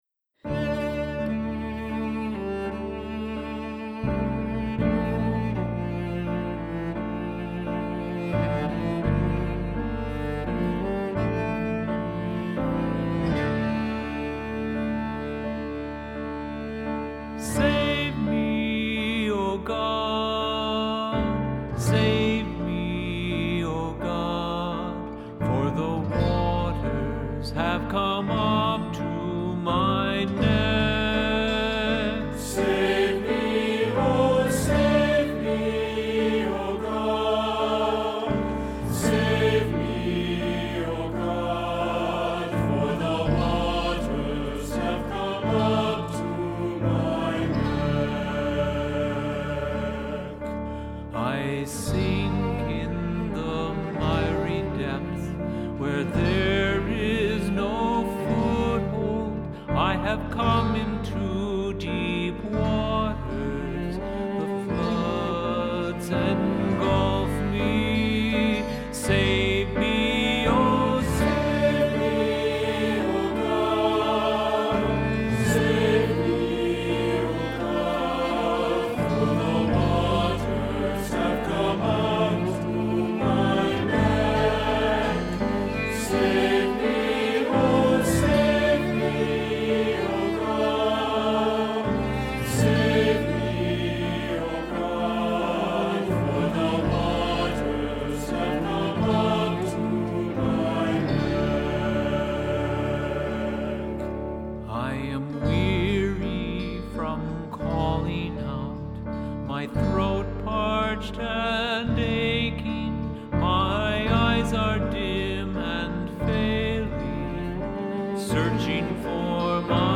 Accompaniment:      Keyboard, Cello
Music Category:      Christian